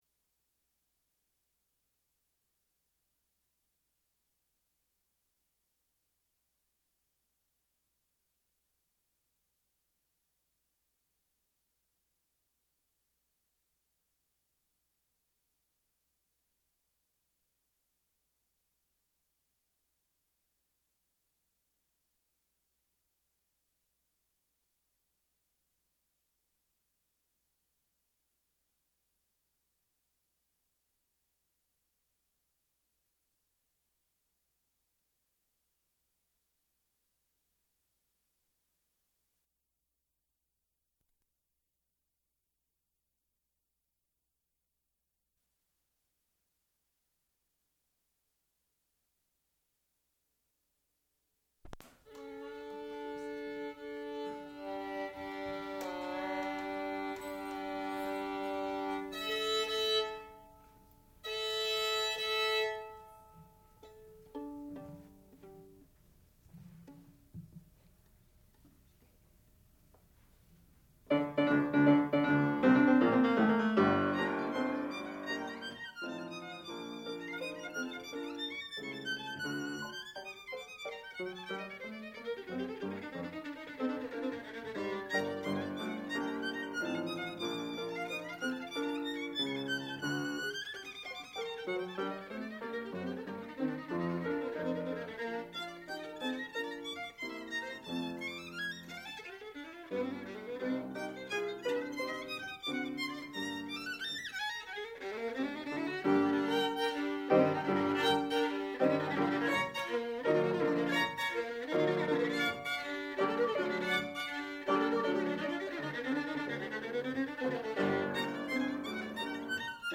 classical music